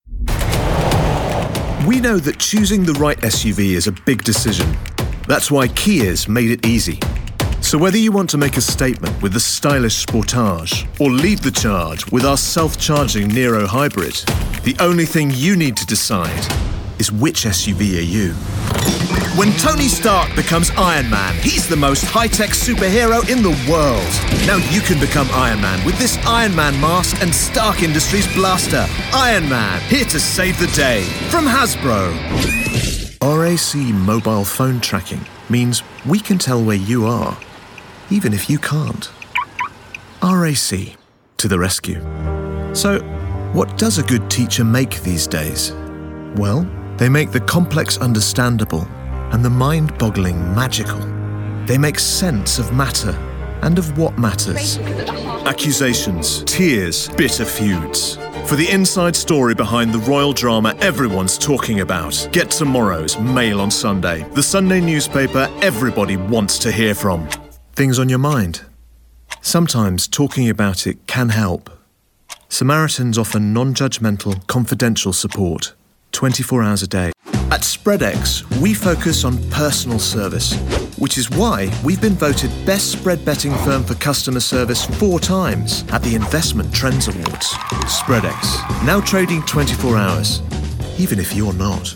Male
English (British)
Warm, convincing, trustworthy voice and genuine RP (Received Pronunciation) accent
My voice has been described as: Rich, Authoritative, Confident, Knowledgeable, Smooth, Compelling, Humorous, Fun, Friendly, Conversational, Guy-Next-Door, Encouraging, Classic, Persuasive, Sexy, Uplifting and More!
Most Popular Commercials